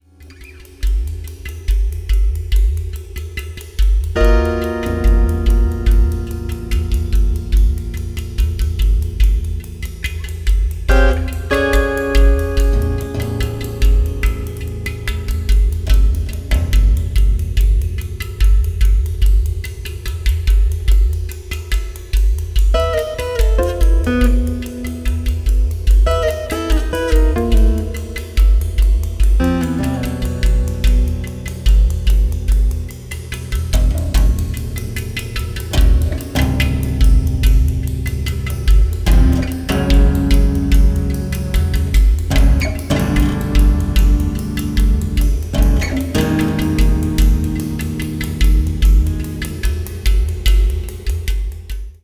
30-string Contra-Alto guitar
Percussion